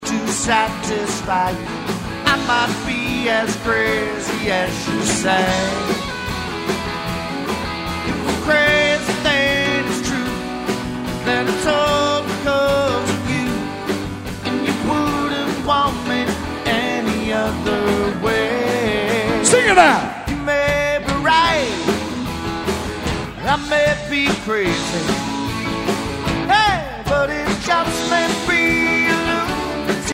And here is a song from the show: